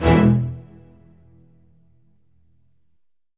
Orchestral Hits
ORCHHIT E2-R.wav